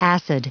Prononciation du mot acid en anglais (fichier audio)
Prononciation du mot : acid